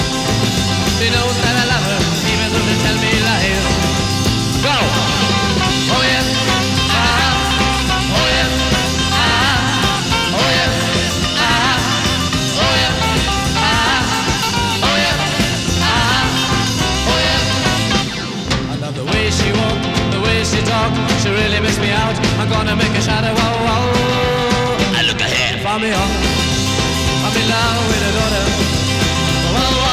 Жанр: Фолк-рок / Рок